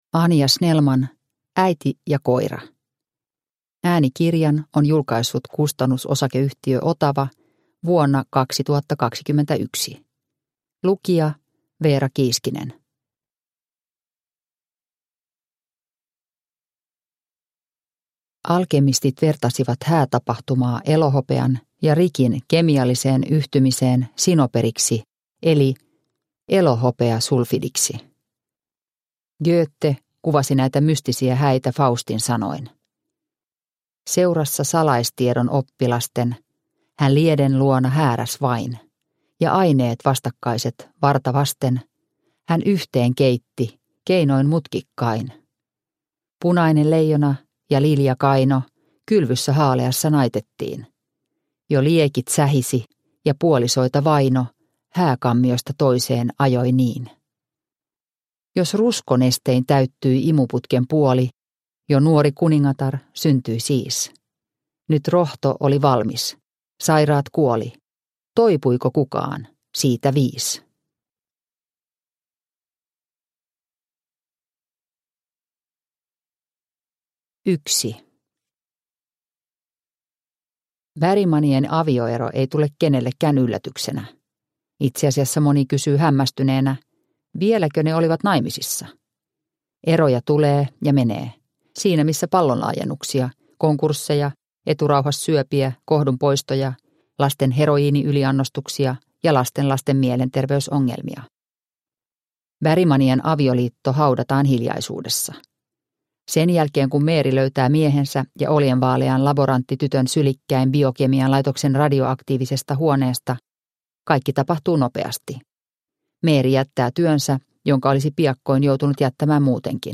Äiti ja koira – Ljudbok – Laddas ner